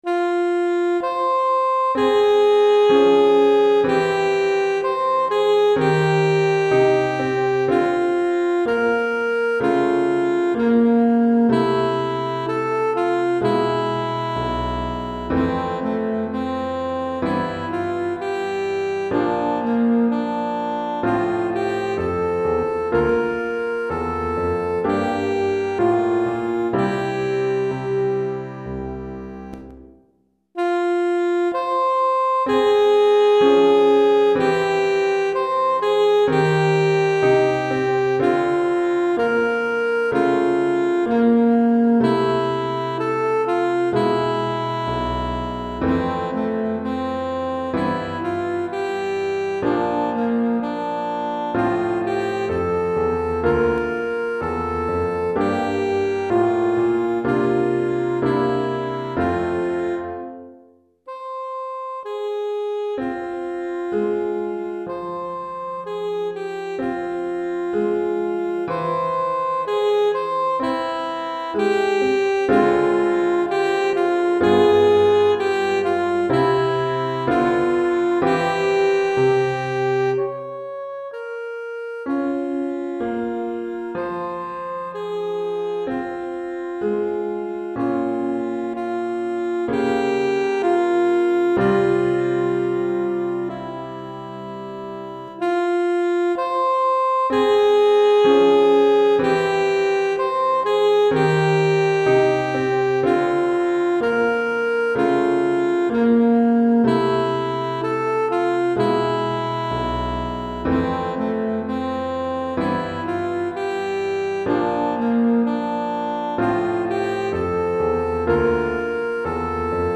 Saxophone Soprano ou Ténor et Piano